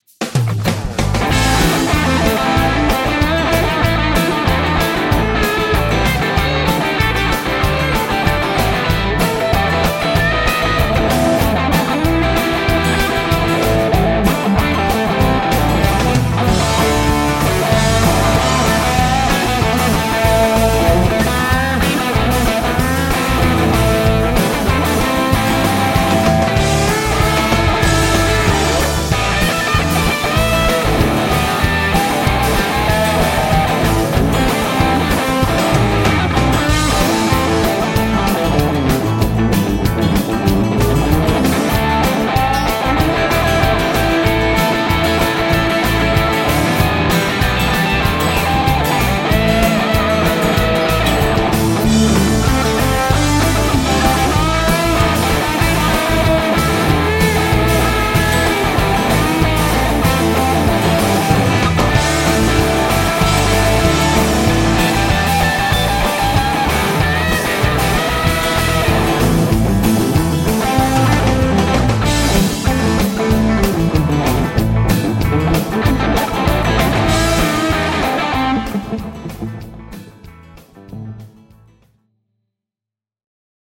rajua menoa
Rajua revitystä. Runsas kaiku vie paikoin vähän puuroksi, mutta meno on kova ja se on tärkeintä.
vähän samoilla linjoilla mennään eli enempi rock/blues kuin kantrityyppistä rouheeta soittoa rouheella soundilla
varsin energistä